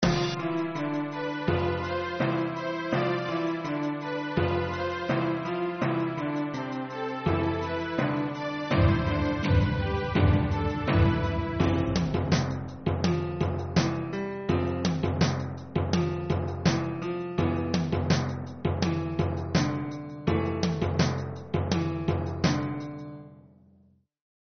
Минусовки (Hip-Hop)
Агрессивные:
4. (инструментальный); темп (91); продолжительность (2:59)